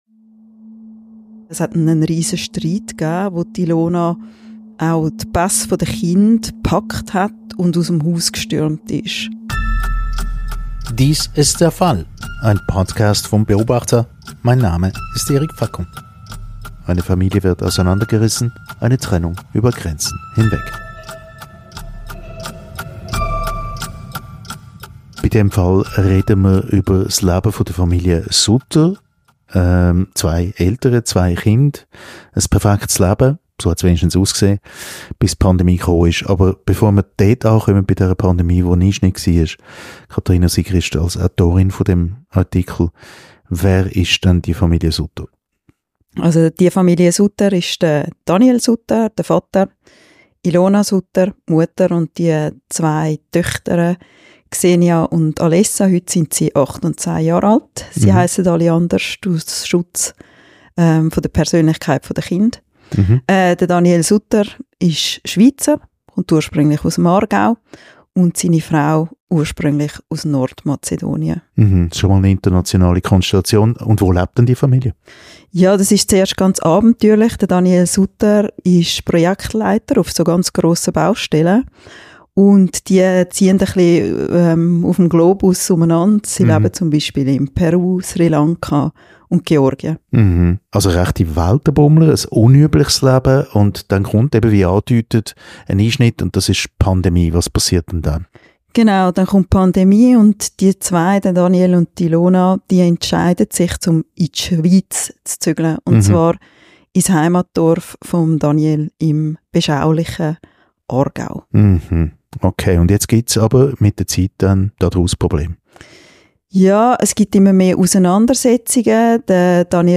Konflikte unter Nachbarn, umstrittene Gerichtsurteile, Erbstreit unter Geschwistern: Der Podcast «Der Fall» ergänzt die Geschichten aus der gleichnamigen Beobachter-Rubrik mit Hintergründen. Journalistinnen und Journalisten schildern im Gespräch